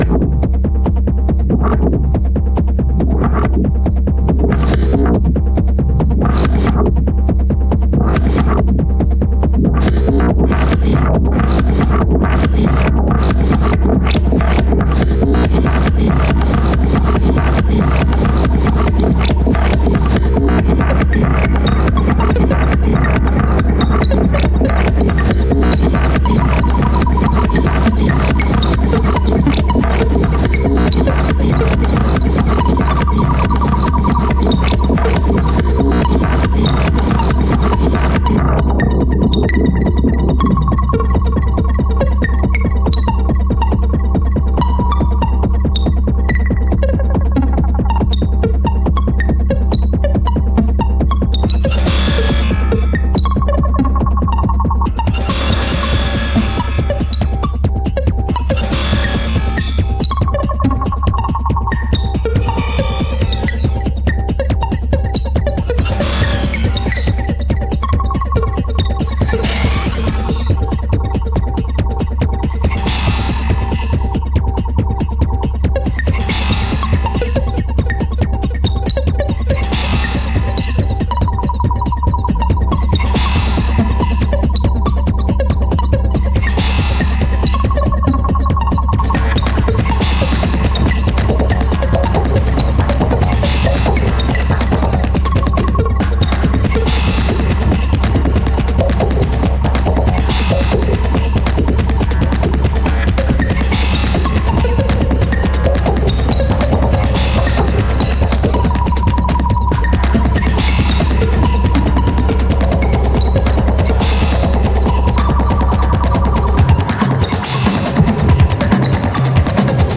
All tracks performed live
Habitat Fotoausstellung at Galerie Brick 5, Wien (AT)